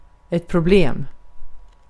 Ääntäminen
Tuntematon aksentti: IPA: /prʊˈbleːm/